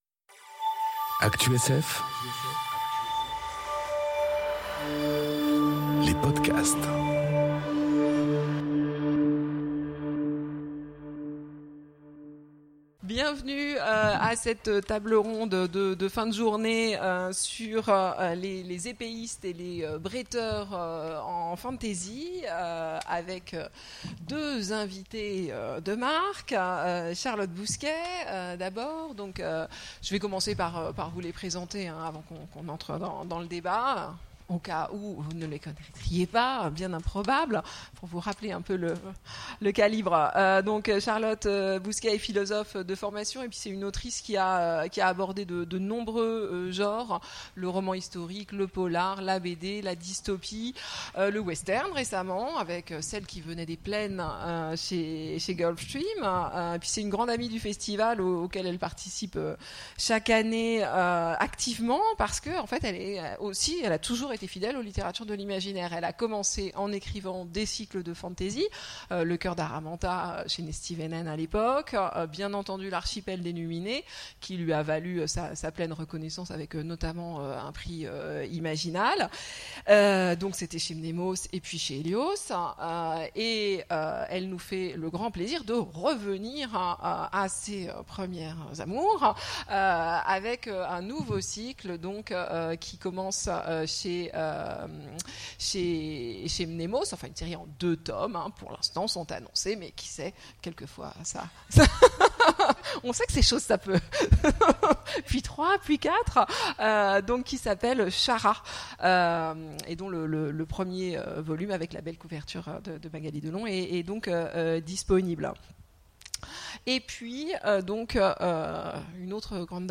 Conférence Épéistes et autres sabreurs... La fantasy, parfois, ça pique enregistrée aux Imaginales 2018